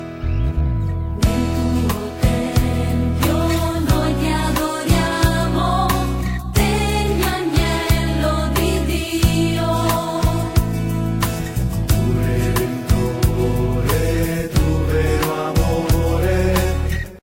canto di lode
introdotto dalla chitarra classica
La parte solista di chitarra � di gusto e non invasiva.